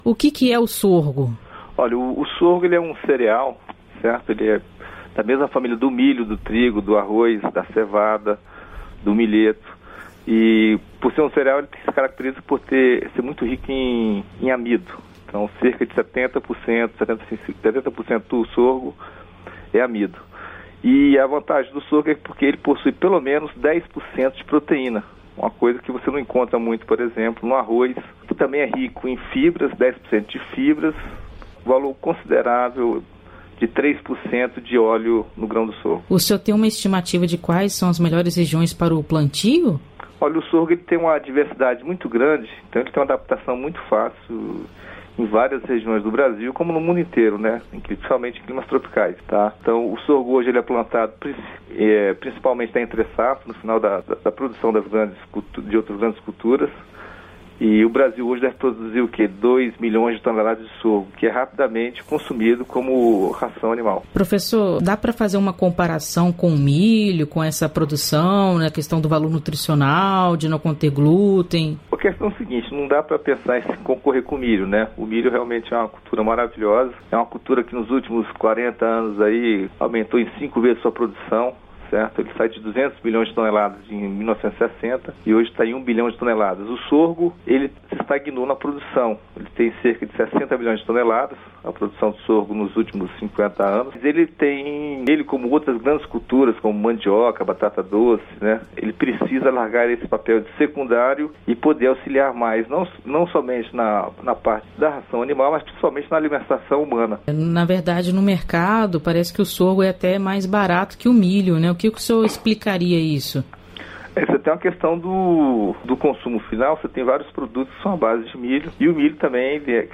Entrevista: Conheça as qualidades do sorgo